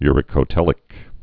(yrĭ-kō-tĕlĭk)